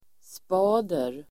Ladda ner uttalet
Uttal: [sp'a:der]